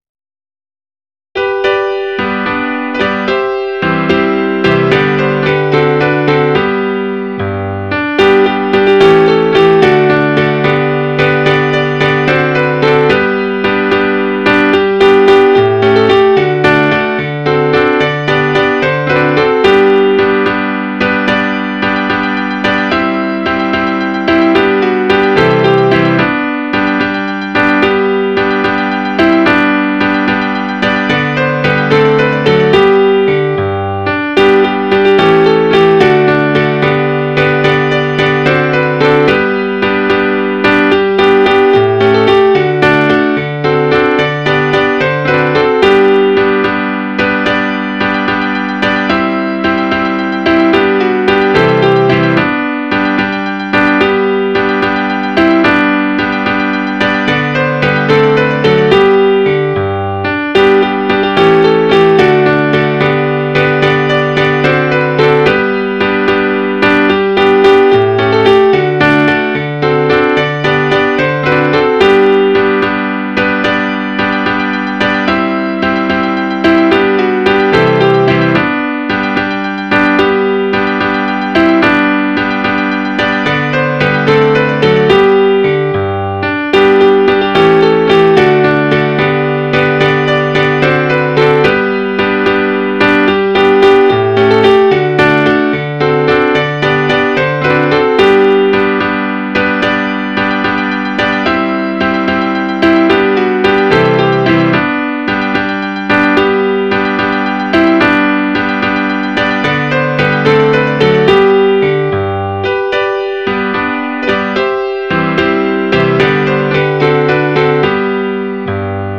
Midi File, Lyrics and Information to Eight Bells
8bells.mid.ogg